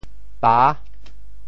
潮州拼音“ba6”的详细信息
潮州府城POJ pă 国际音标 [pa]